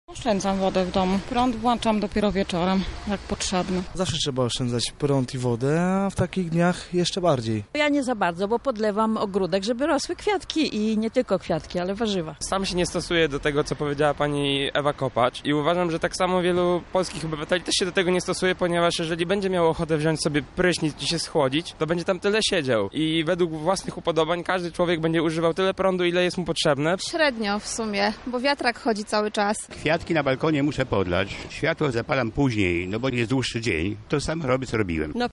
Zapytaliśmy mieszkańców Lublina czy oszczędzają energię oraz wodę.